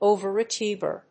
アクセント・音節òver・achíeve